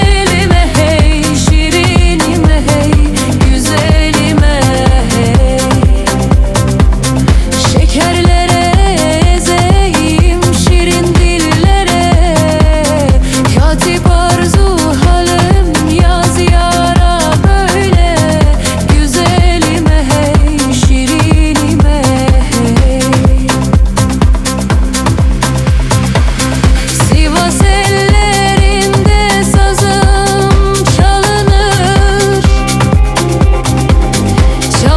Dance African Afro-Beat
Жанр: Танцевальные